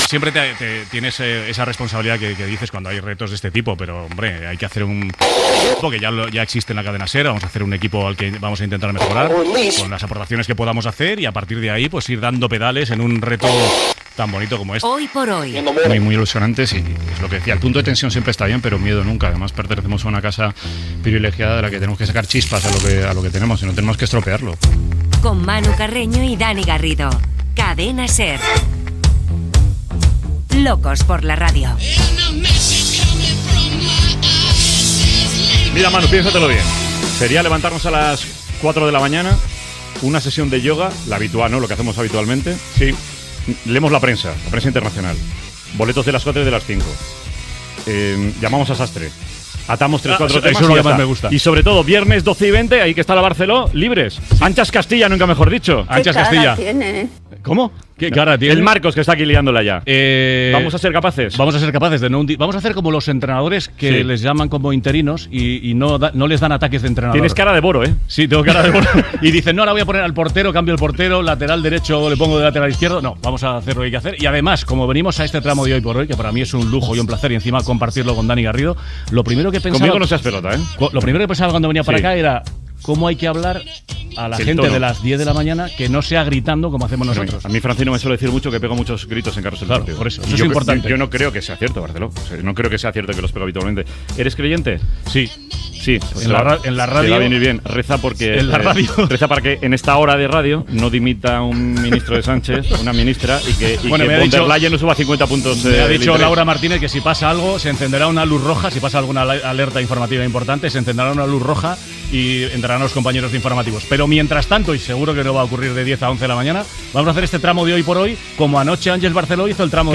Espai especial fet per celebrar el Día Mundial de la Radio "Locos por la radio". Diàleg entre els presentadors ocasionals del programa, equip, espai del Comando N sobre els primers records futbolítics
Info-entreteniment